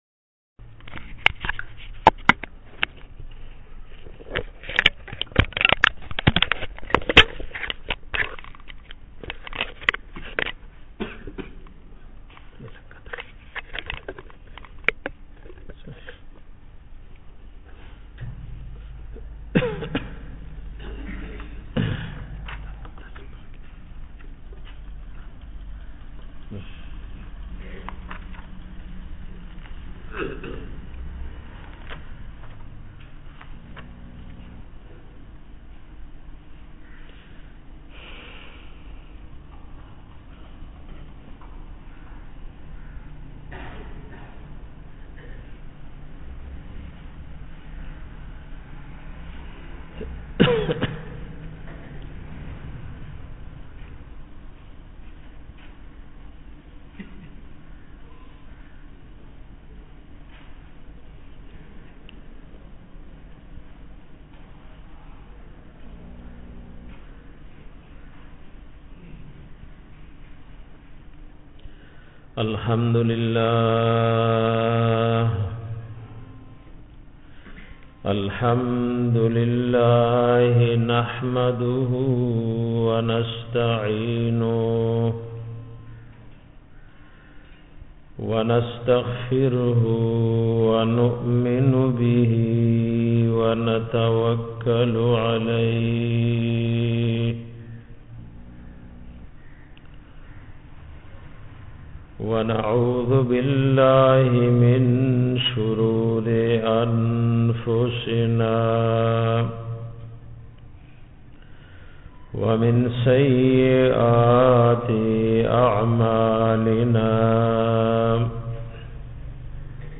bayan pa bara do garibai ka